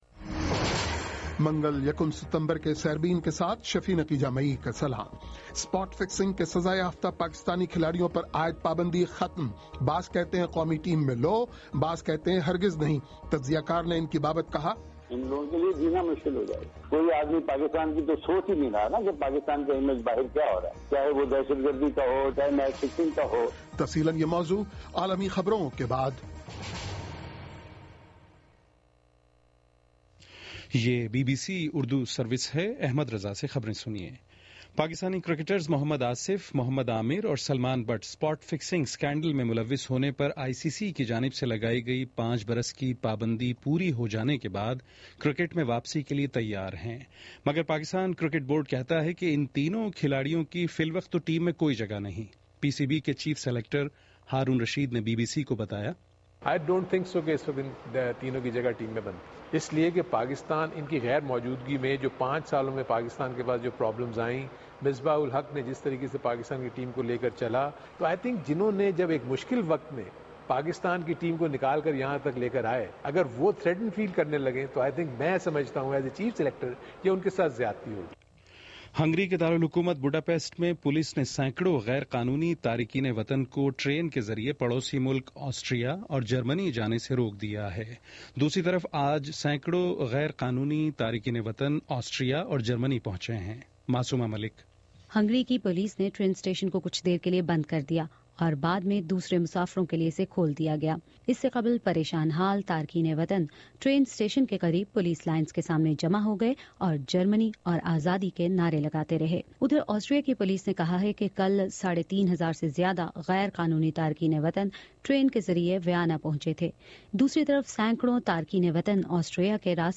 منگل 01 ستمبر کا سیربین ریڈیو پروگرام